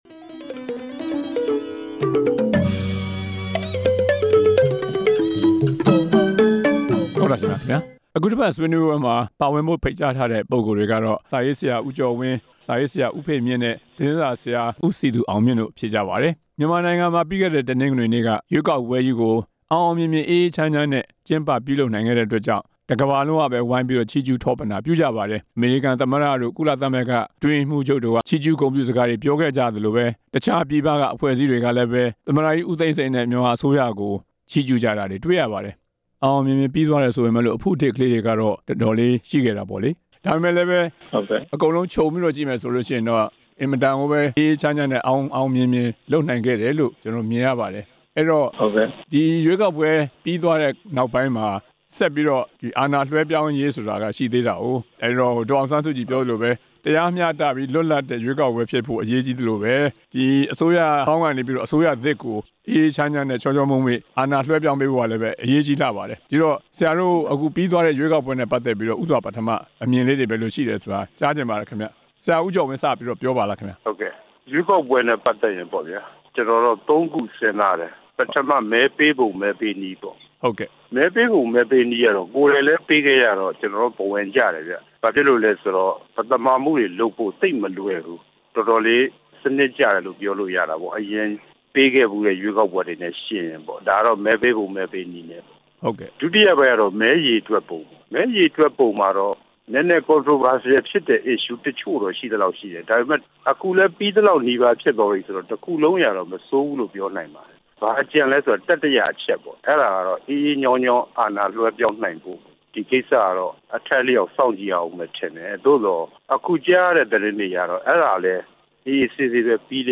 NLD ပါတီ ရင်ဆိုင်သွားရ မယ့် လုပ်ငန်းကိစ္စရပ်တွေ အကြောင်း ဆွေးနွေးချက်